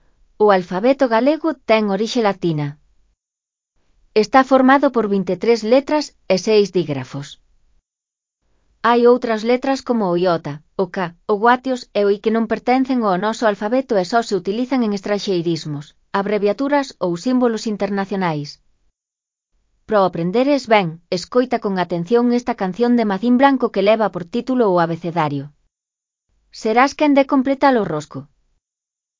canción